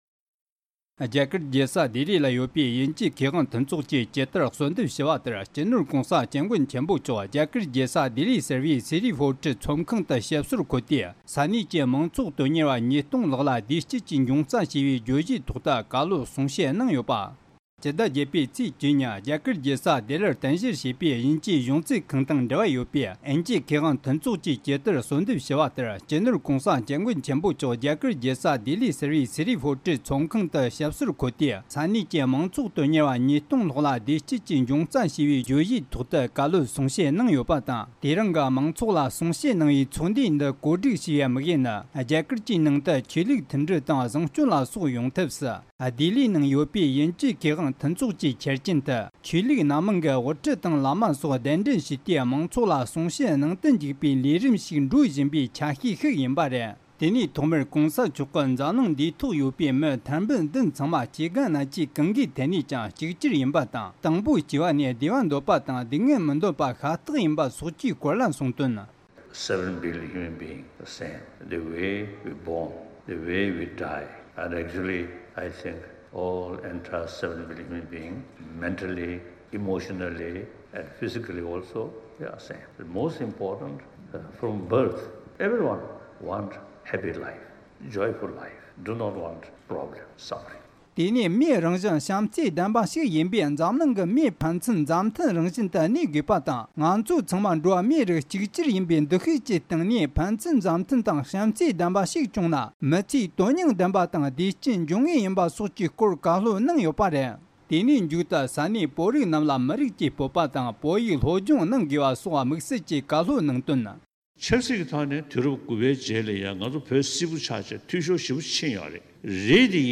སྒྲ་ལྡན་གསར་འགྱུར། སྒྲ་ཕབ་ལེན།
རྒྱ་གར་གྱི་རྒྱལ་ས་ལྡི་ལིའི་ནང་གི་དབྱིན་ཇིའི་མཁས་དབང་མཐུན་ཚོགས་(Association of British Scholars)་ཞེས་པས་ཇི་ལྟར་གསོལ་འདེབས་ཞུས་པ་བཞིན་བོད་ཀྱི་བླ་ན་མེད་པའི་དབུ་ཁྲིད་སྤྱི་ནོར་༧གོང་ས་༧སྐྱབས་མགོན་ཆེན་པོ་མཆོག་གིས་འདི་ཚེས་༡༠་ཉིན་ལྡི་ལིའི་ནང་གི་Siri Fort་ཚོགས་ཁང་ཆེན་མོའི་ནང་མང་ཚོགས་ཉི་སྟོང་ལྷག་ལ་བདེ་སྐྱིད་ཀྱི་འབྱུང་རྩ་ཞེས་བའི་བརྗོད་གཞིའི་ཐོག་བཀའ་སློབ་བཀྲིན་ཆེ་གནང་མཛད་འདུག